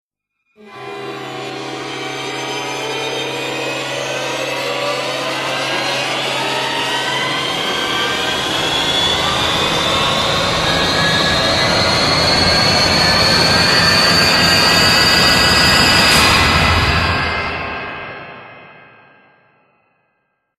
Hiệu ứng âm thanh Kinh dị sắp Biết được sự thật